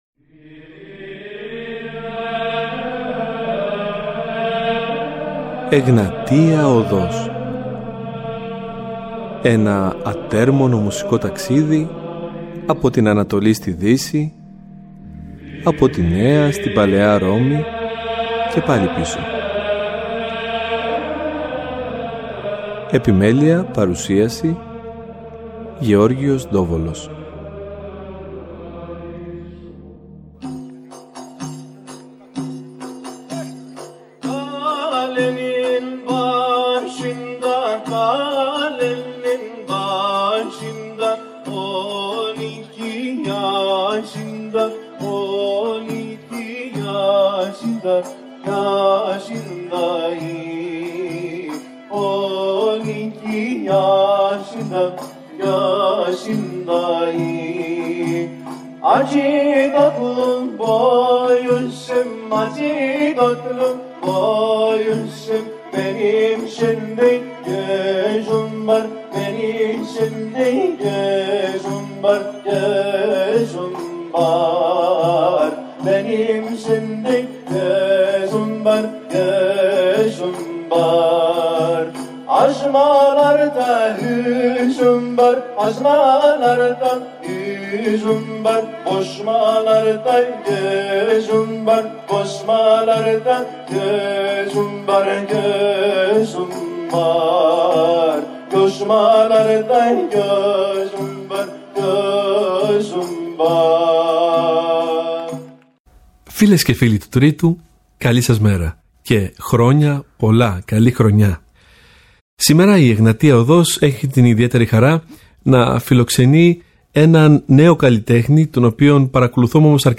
Στο πλαίσιο του δίπτυχου αφιερώματός μας, θα έχουμε την χαρά να τον ακούσουμε να τραγουδάει, να ψάλλει, μα κυρίως να μιλά για την μέχρι τούδε πορεία του και τα βιώματά του.